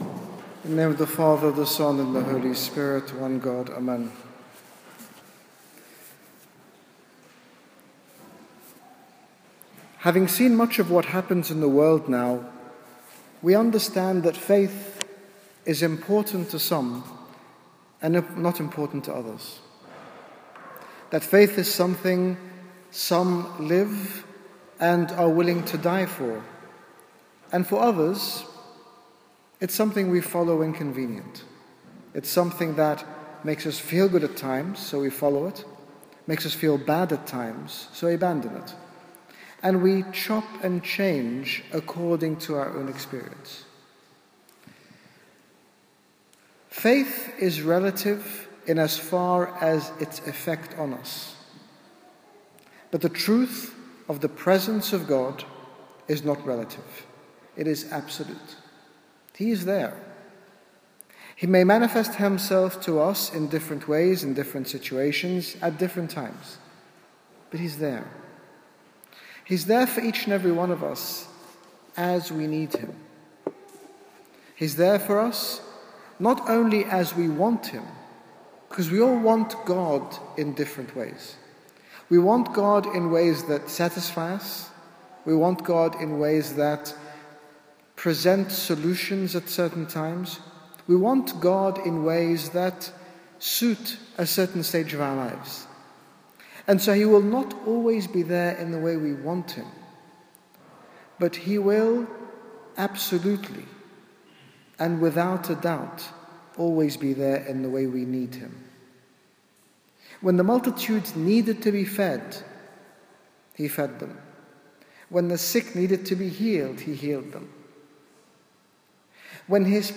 In this sermon His Grace Bishop Angaelos, General Bishop of the Coptic Orthodox Church in the United Kingdom, talks about the importance of Faith and the reality of the presence of God.